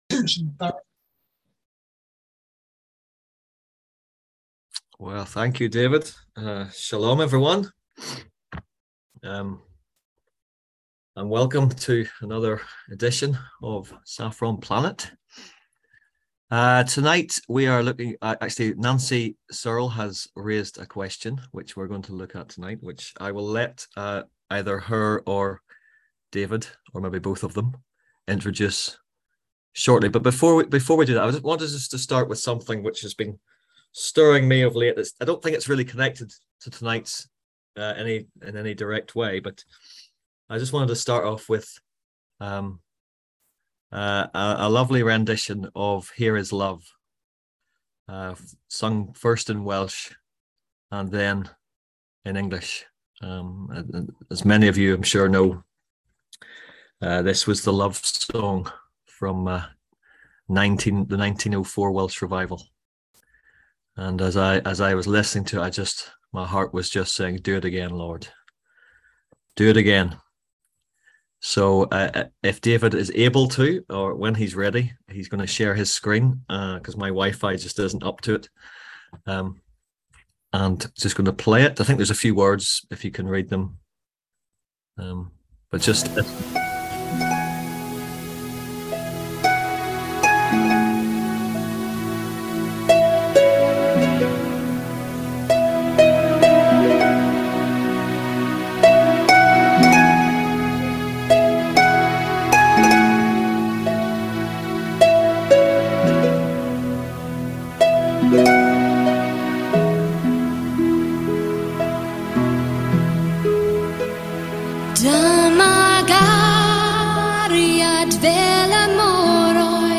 On October 10th at 7pm – 8:30pm on ZOOM ASK A QUESTION – Our lively discussion forum.